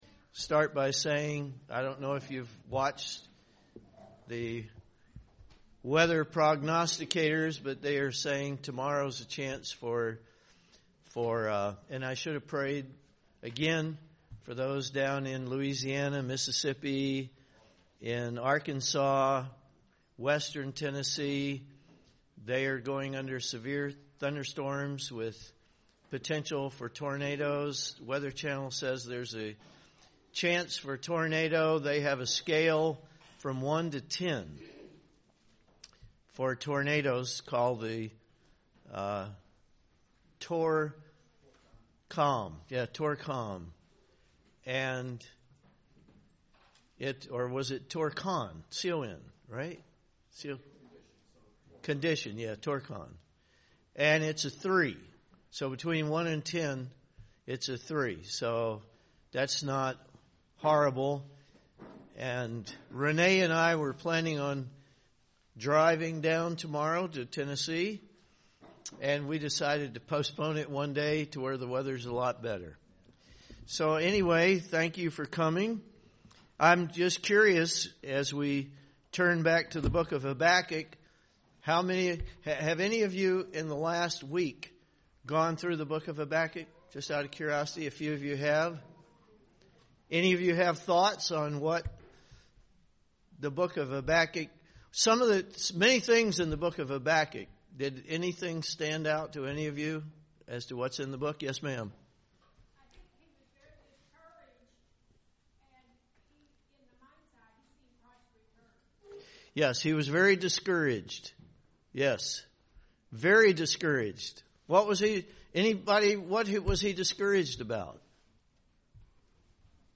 Bible Study on Habakkuk
Given in Dayton, OH